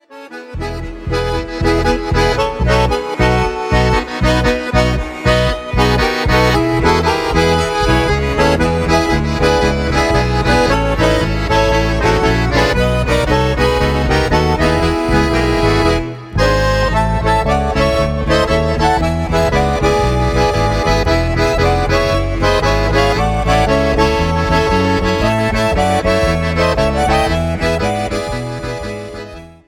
Slow-Fox